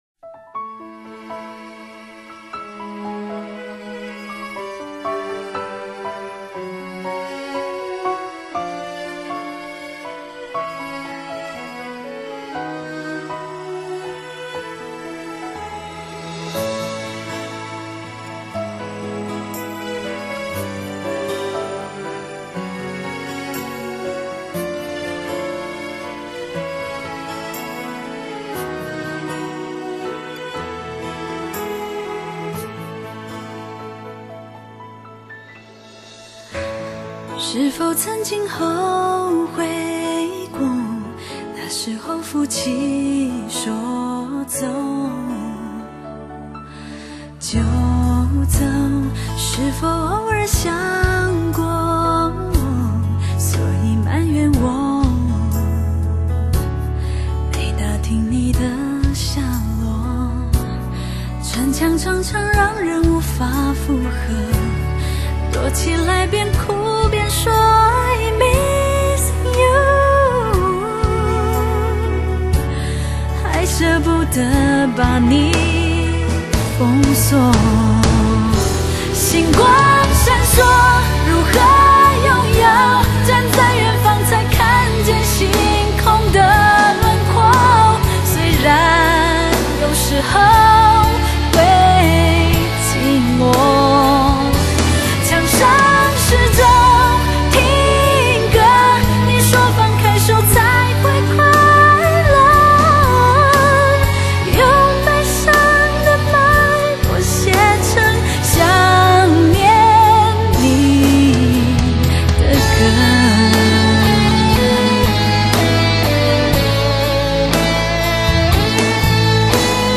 绝对值得珍藏的纯净好声音